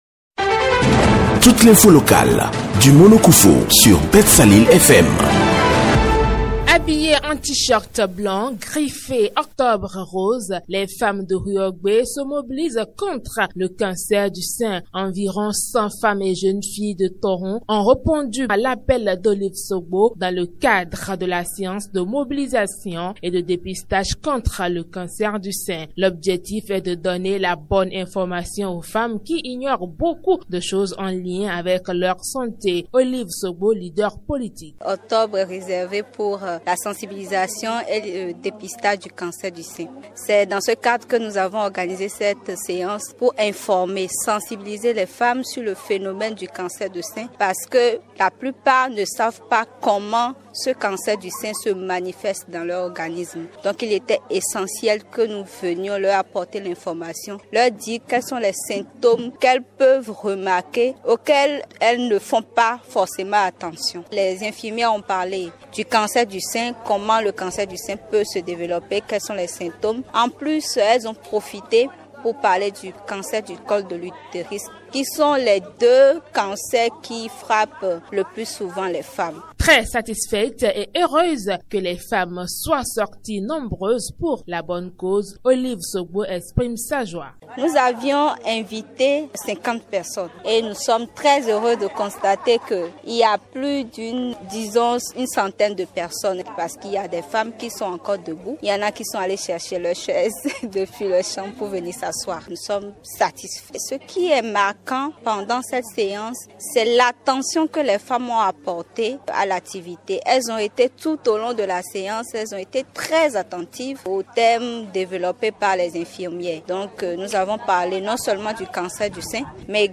Voici son reportage